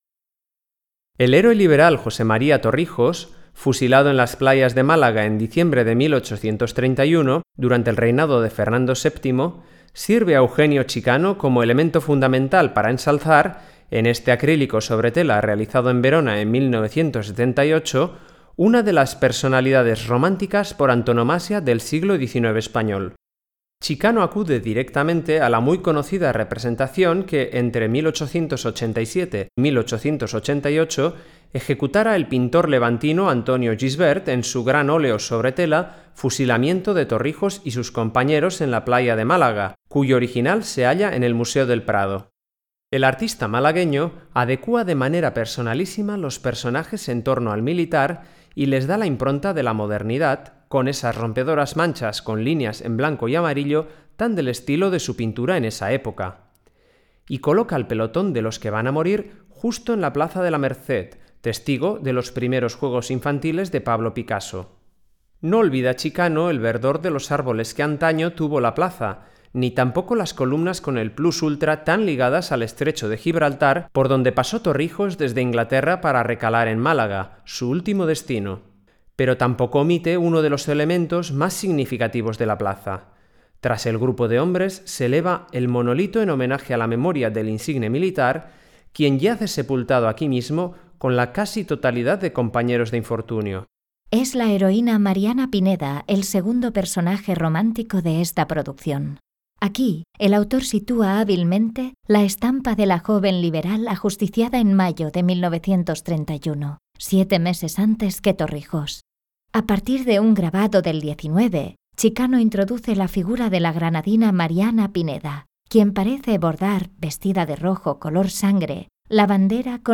Audioguías